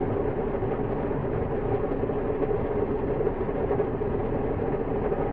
drill.ogg